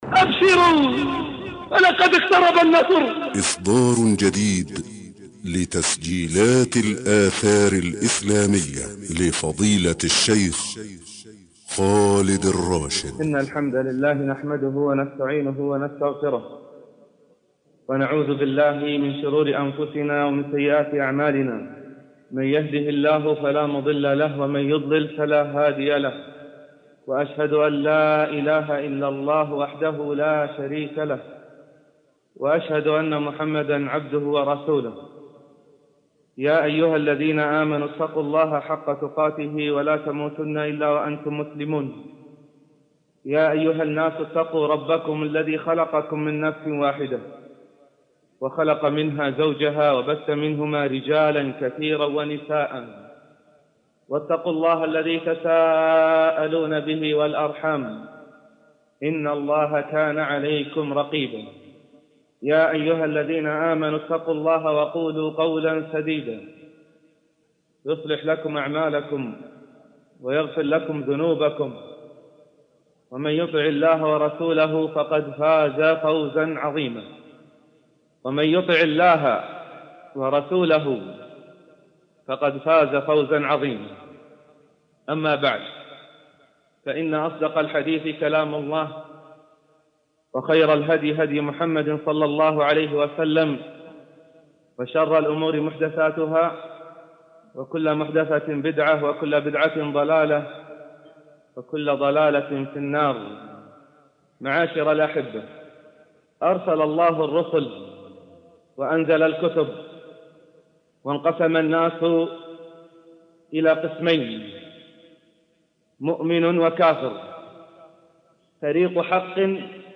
محاضرة صوتية ابشروا لقد جاء النصر
محاضرة: أبشروا فلقد اقترب النصر
لفضيلة الشيخ خالد الراشد – تسجيلات الآثار الإسلامية